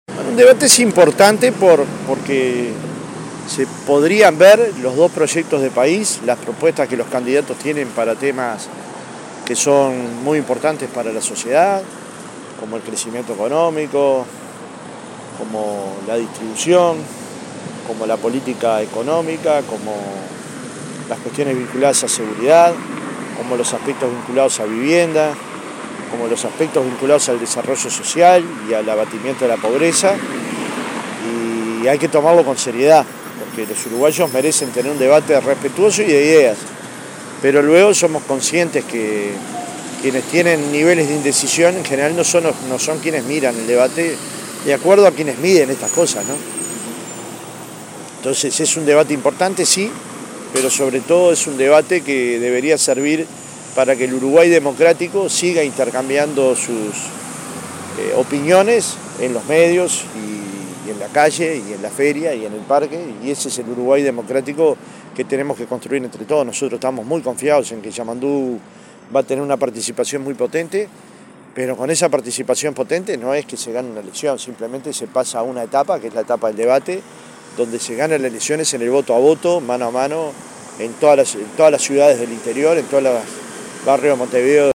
El presidente del Frente Amplio Fernando Pereira, dijo a Radio Monte Carlo de Montevideo  que “el debate es importante” y recalcó que “hay que tomarlo con seriedad”.